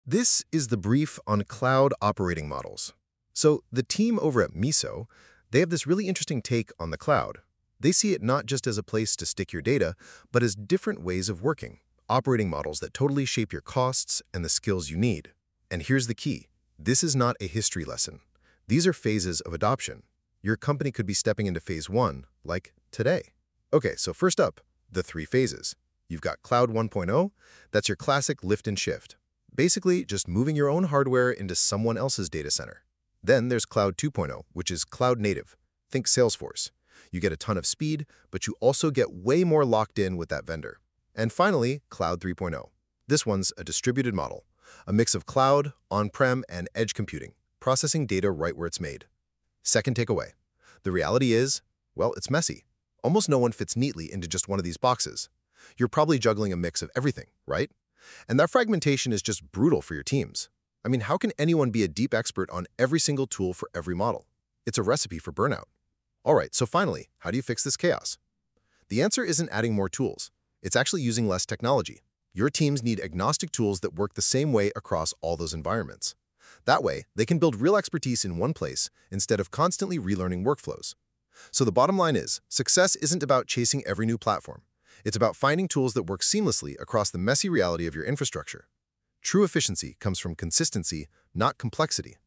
Short on time? 90-second audio summary…
A quick listen, generated using Google Notebook LM.